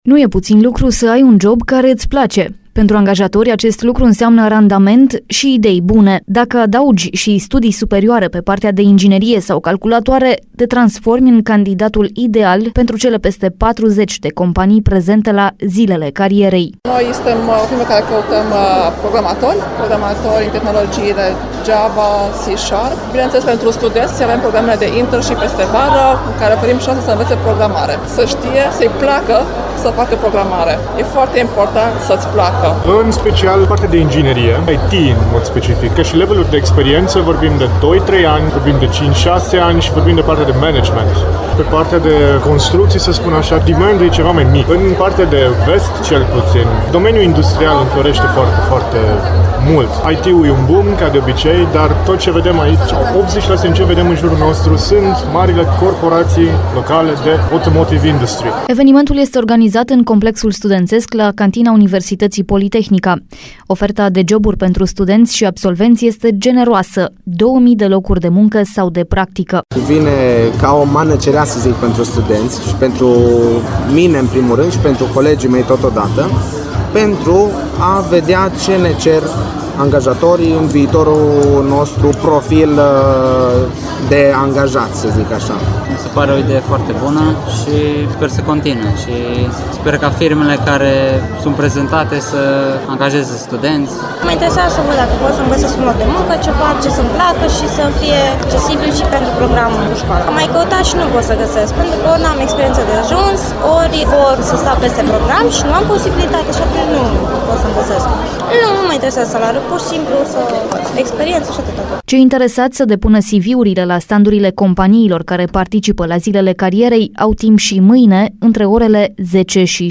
a discutat cu studenții dar și cu reprezentanții companiilor prezente la Zilele Carierei.